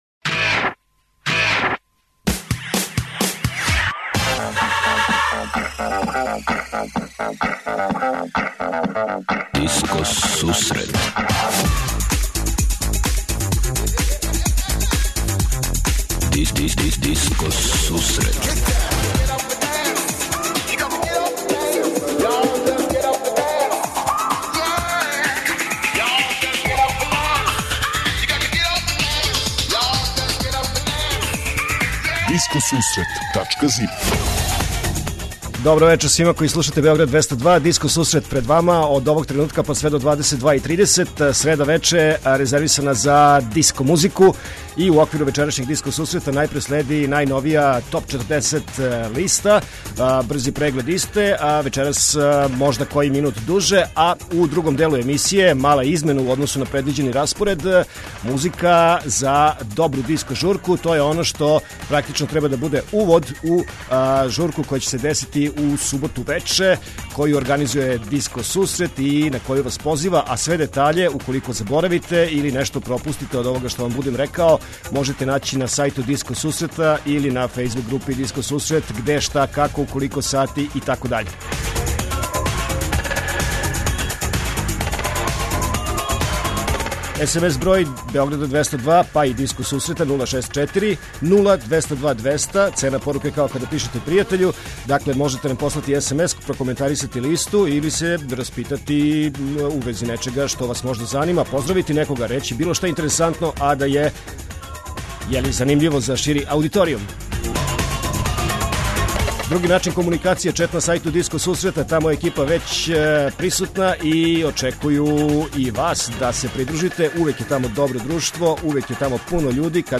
На почетку емисије - Диско Сусрет Топ 40 - Топ листа 40 највећих светских диско хитова.
У другом делу емисије - музика за добру диско журку.